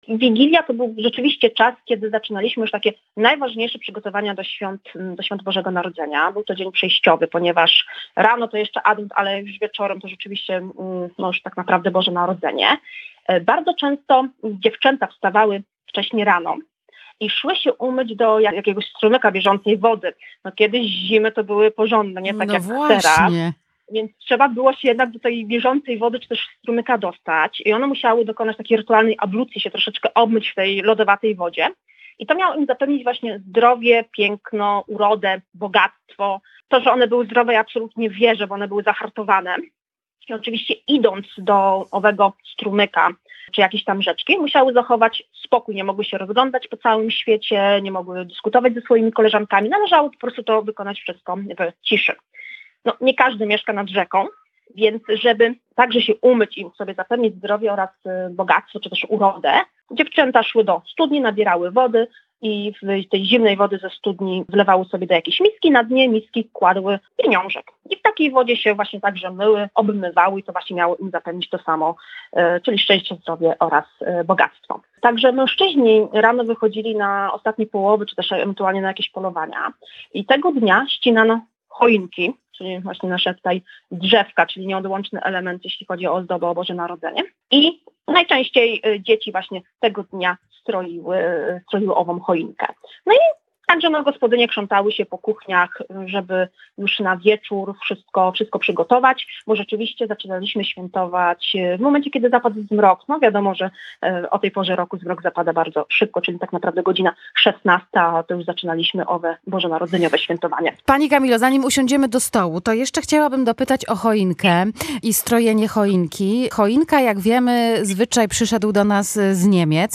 – Adwent to czas przygotowań do Bożego Narodzenia. Wigilia również. Sama wigilia to czas wróżb i to już od samego rana – zaznacza etnolog.